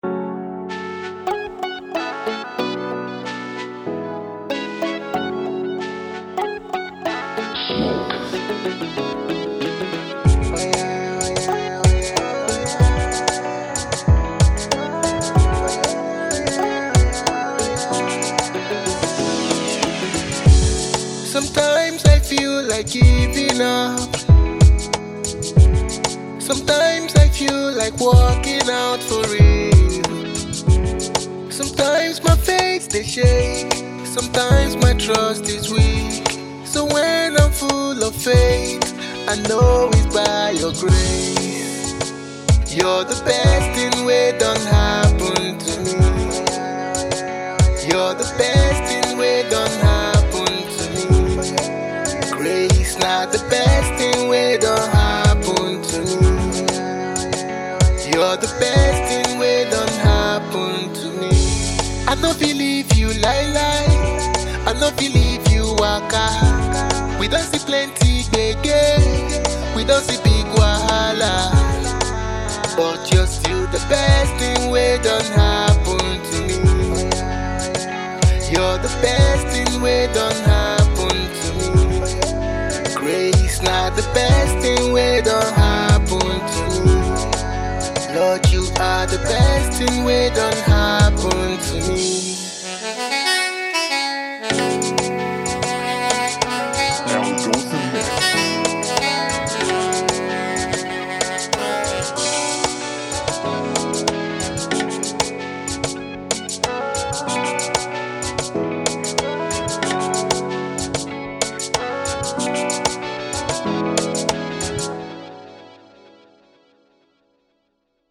AfroGospel artiste and songwriter